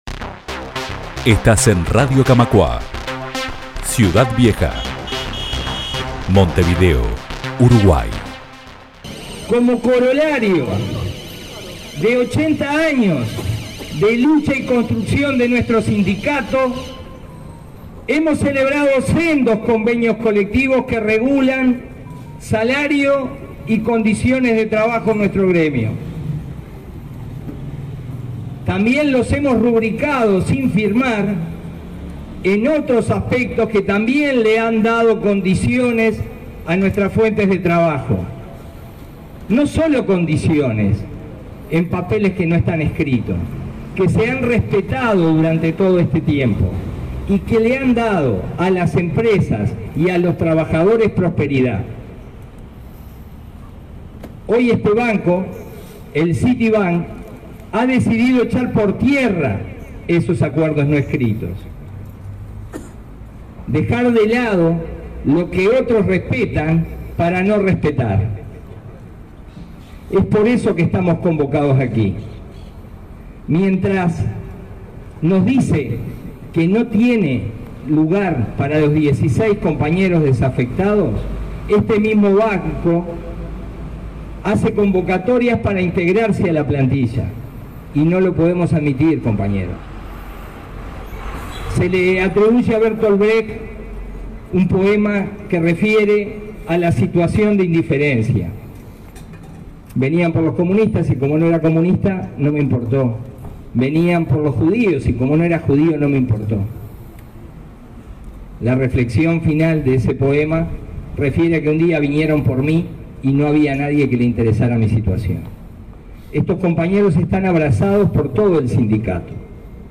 Sobre las 16 horas, cientos de bancarios se concentraron en la esquina de Misiones y Cerrito, enfrente a la sede central del banco estadounidense, para hacer sentir su voz.